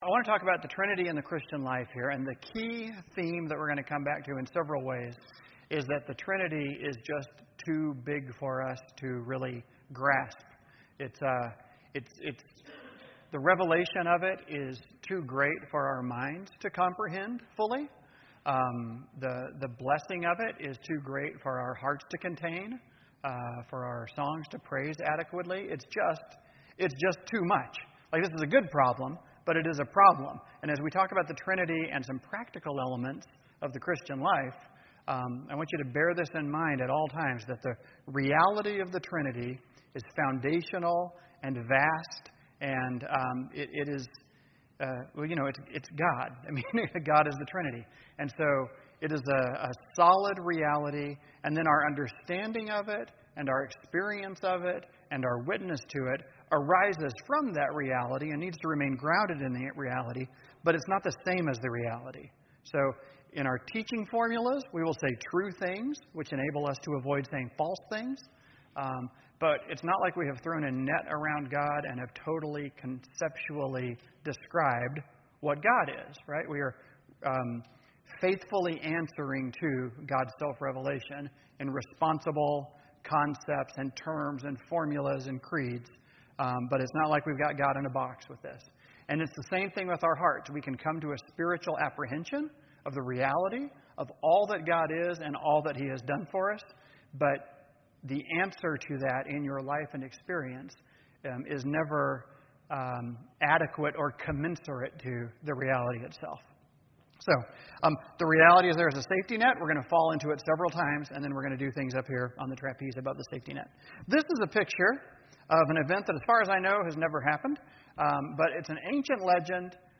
Lectures on the Trinity